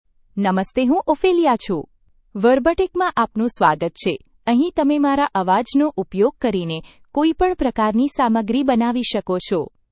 Ophelia — Female Gujarati (India) AI Voice | TTS, Voice Cloning & Video | Verbatik AI
OpheliaFemale Gujarati AI voice
Voice sample
Listen to Ophelia's female Gujarati voice.
Ophelia delivers clear pronunciation with authentic India Gujarati intonation, making your content sound professionally produced.